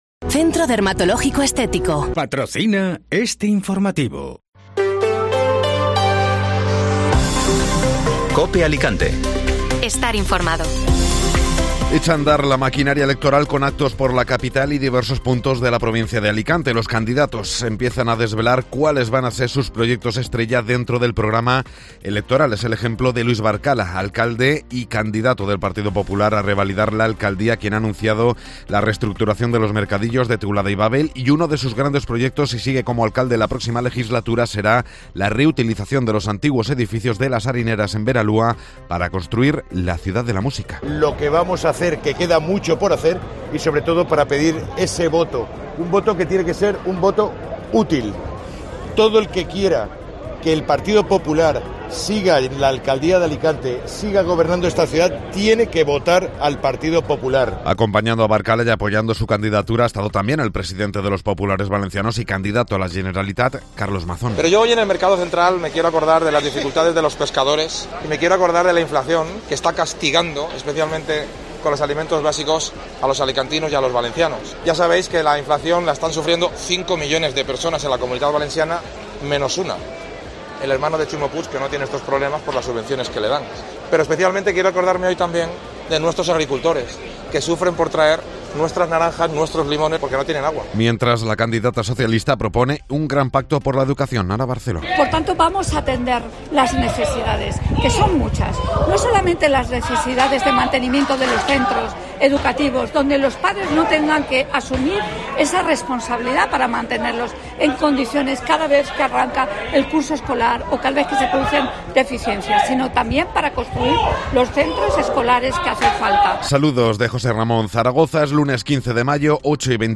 Informativo Matinal (Lunes 15 de Mayo)